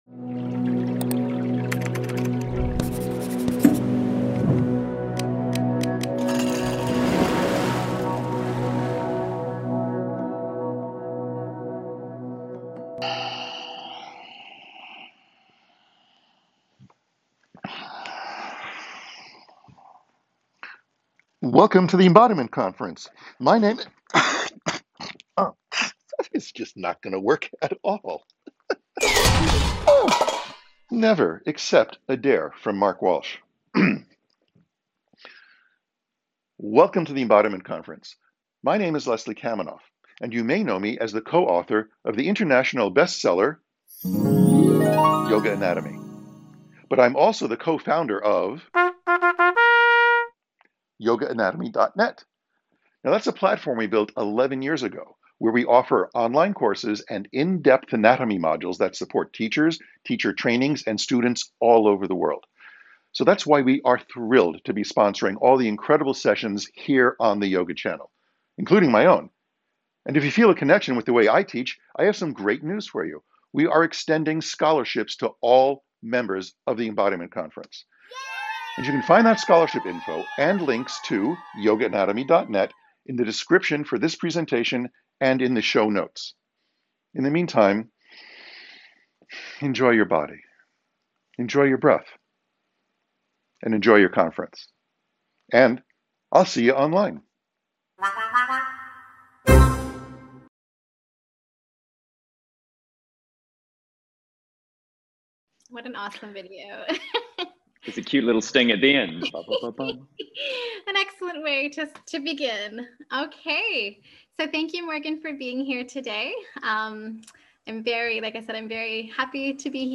Internal Flow, Yoga & the Neurobiology of Wellness Intermediate understanding Some standing/ movement Likely soothing What is Flow, and how can we cultivate it on all the different levels we inhabit as humans?